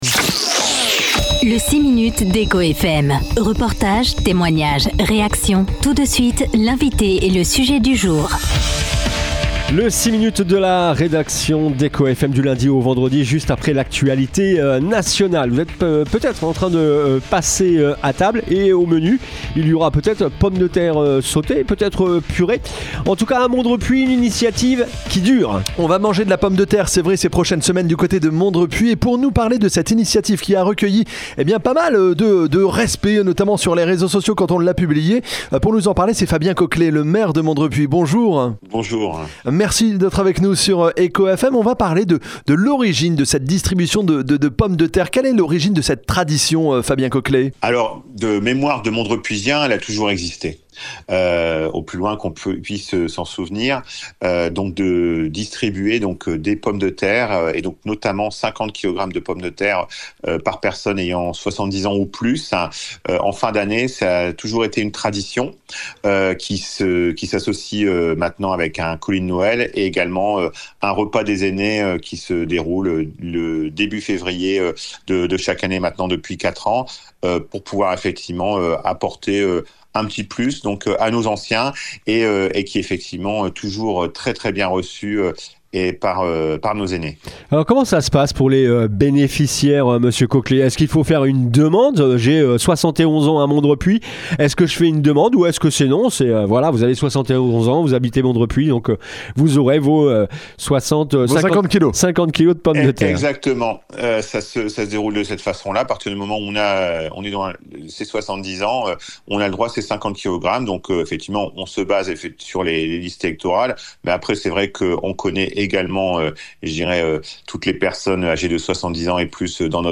Ce vendredi 31 octobre, le maire de Mondrepuis Fabien Coquelet était l'invité de la rédaction d'Echo FM.